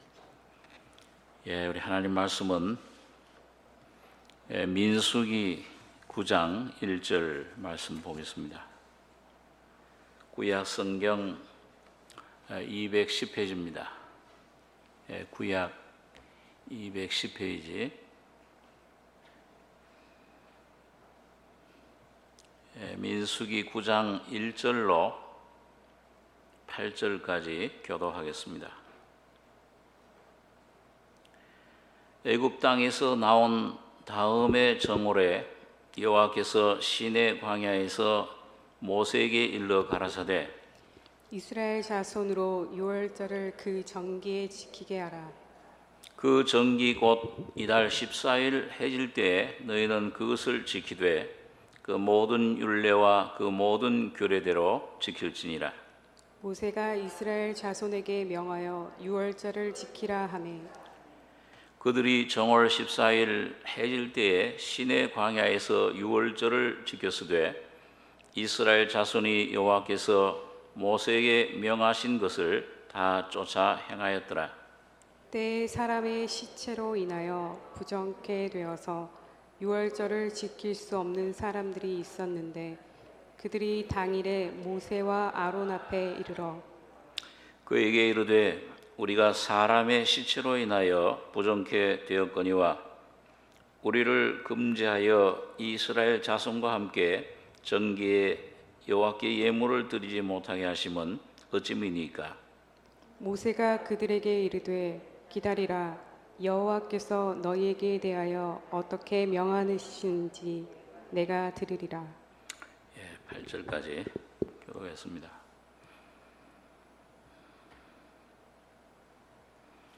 수요예배